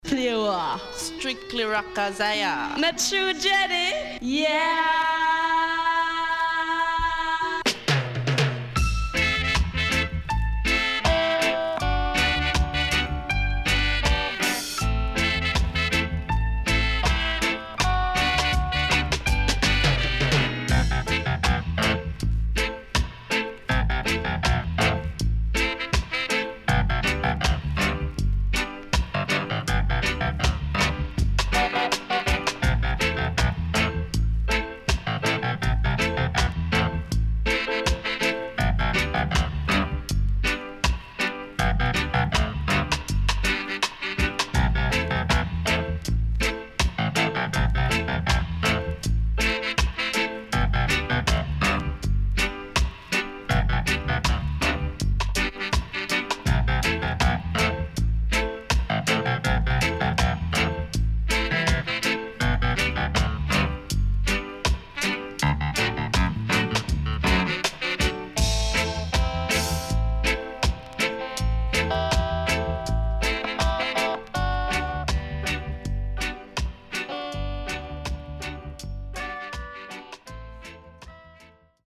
riddim
SIDE A:少しチリノイズ入りますが良好です。